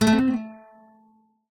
guitar_ac1a.ogg